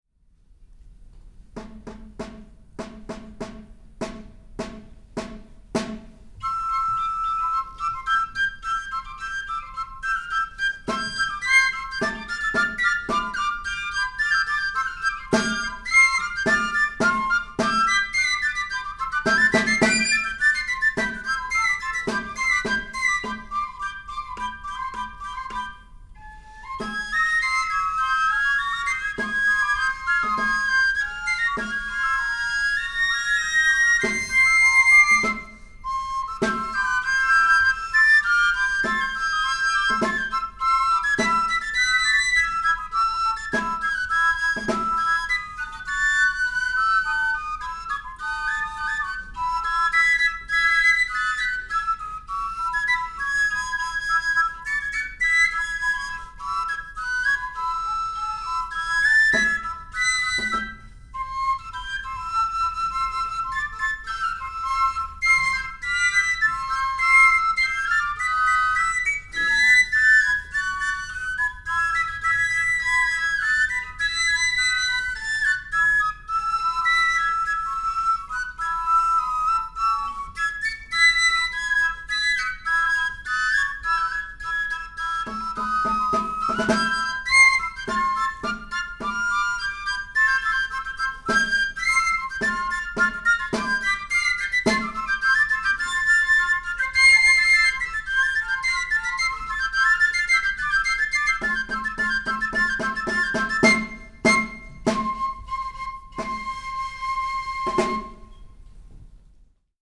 Nocturno y final (fragmento) de Rodrigo A. de Santiago. Int. Berziztu Txistu Banda